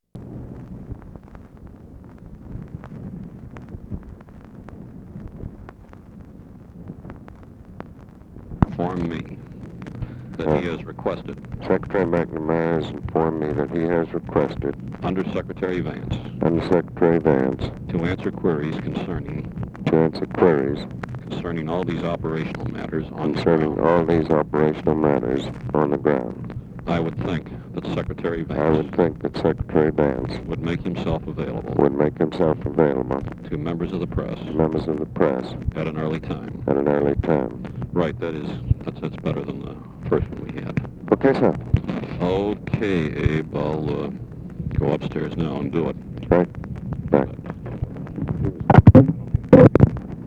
Conversation with ABE FORTAS, OFFICE CONVERSATION and GEORGE REEDY
Secret White House Tapes